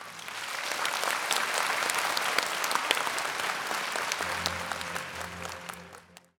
sfx_crowd_clap.ogg